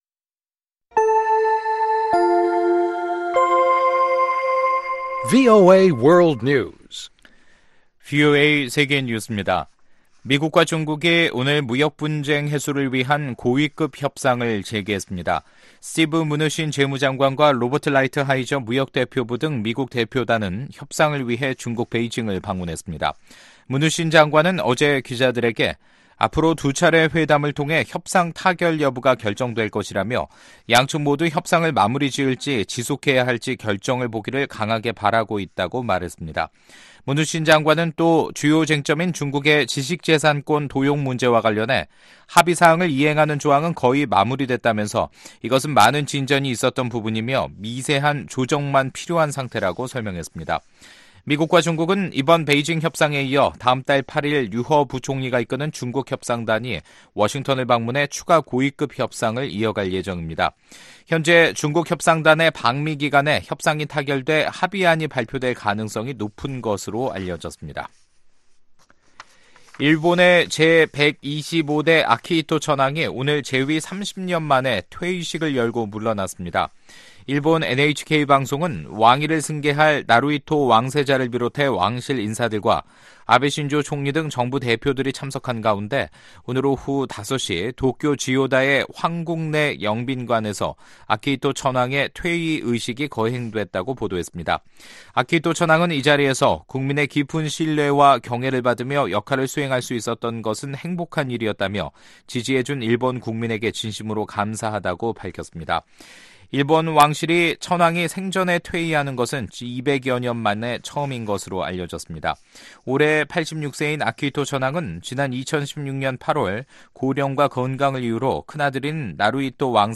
VOA 한국어 간판 뉴스 프로그램 '뉴스 투데이', 2019년 4월 30일 2부 방송입니다. 마이크 폼페오 미국 국무장관은 3차 미-북 정상회담이 열리기 위해선 “실질적 진전을 이룰 수 있는 여건”이 마련돼야 한다고 말했습니다. 핵확산금지조약, NPT 평가회의를 위한 사전 준비회의에서 여러 나라들은 미-북 대화 등을 통한 문제 해결을 촉구했습니다.